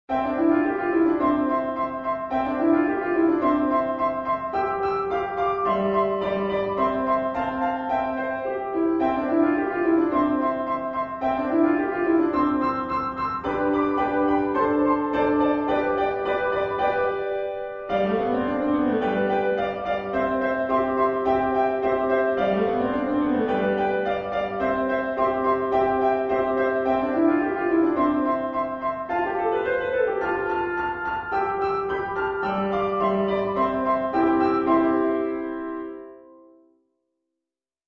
リピートは基本的に省略していますが、D.C.を含むものは途中のリピートも全て再現しています